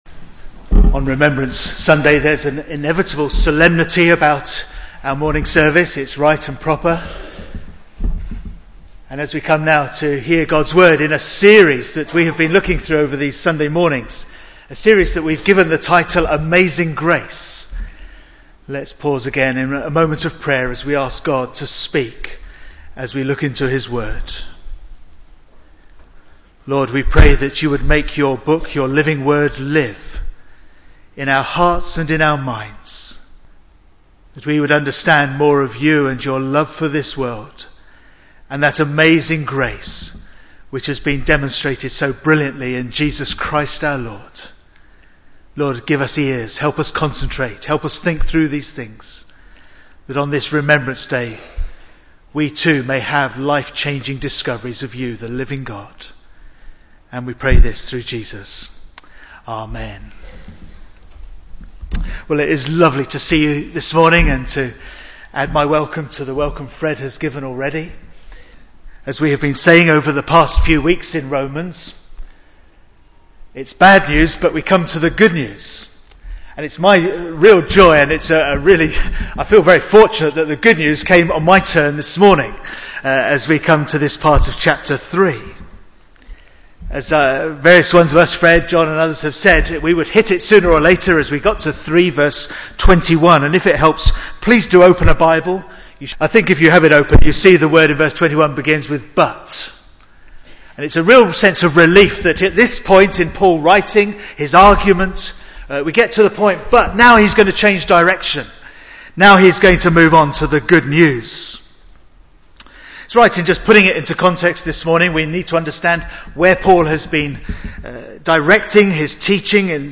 2010 Service type: Sunday AM Bible Text